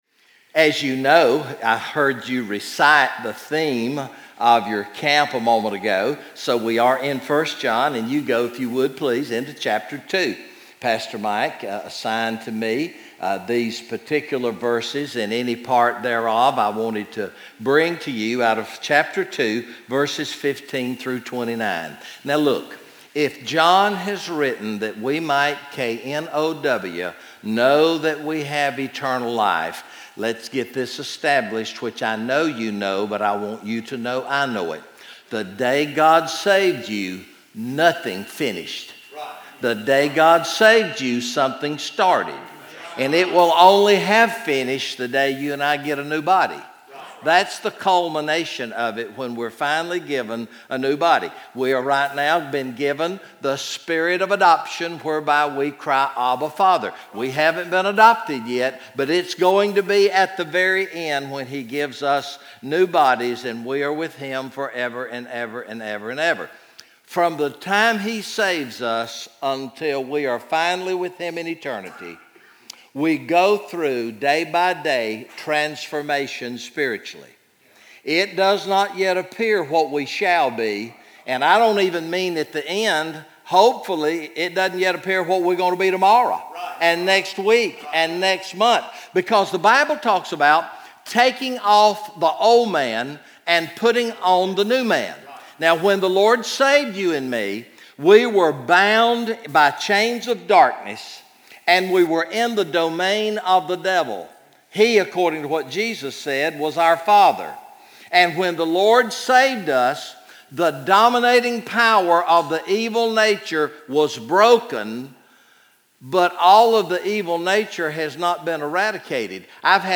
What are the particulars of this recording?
Message #04 from the ESM Summer Camp sermon series through the book of First John entitled "You Can Know"